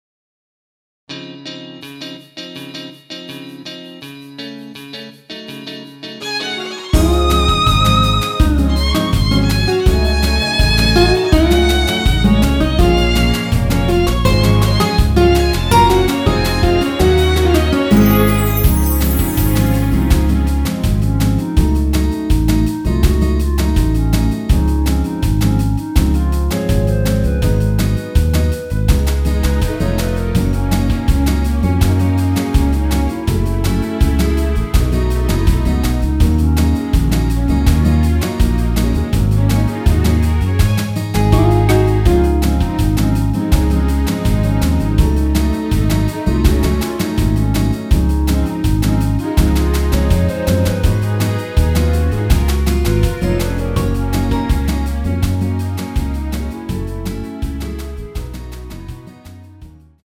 원키 멜로디 포함된 MR입니다.
Db
앞부분30초, 뒷부분30초씩 편집해서 올려 드리고 있습니다.